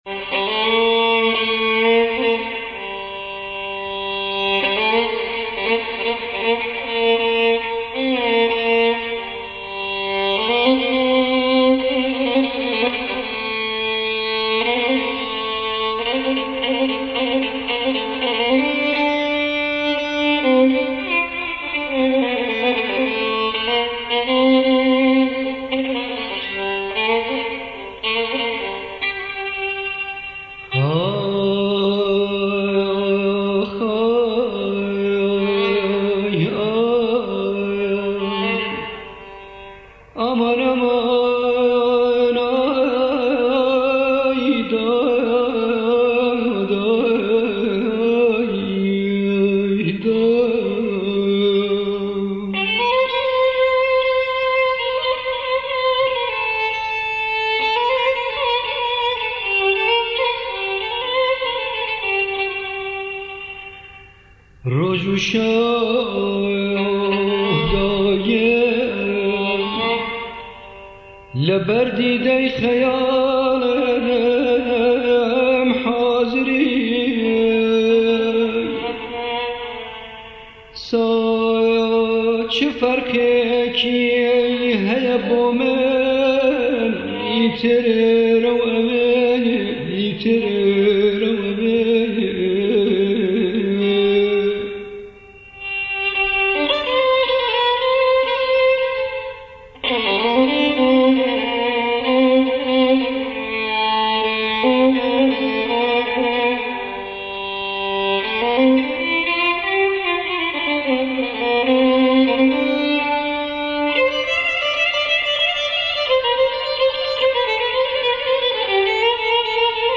ڤایۆلین